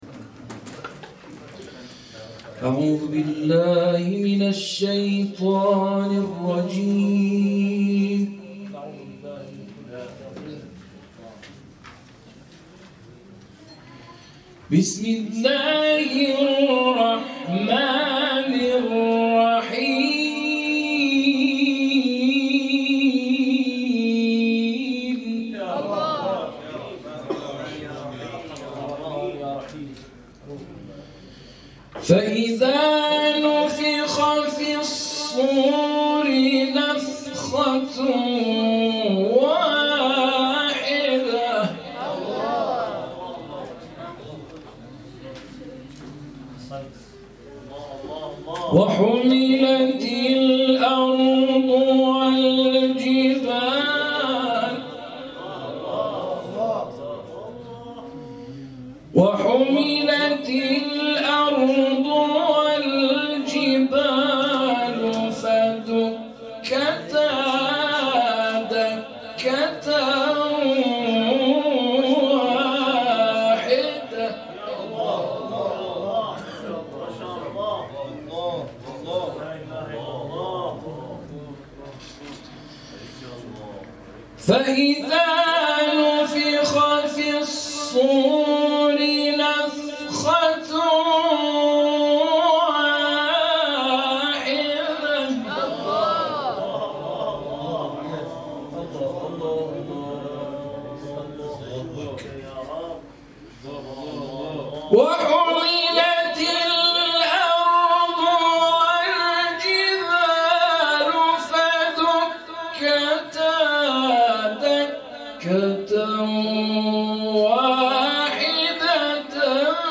در محفل شب گذشته به اهمیت قرائت زیبا ائمه جماعت و حکمت قرائت قرآن در نماز اشاره شد.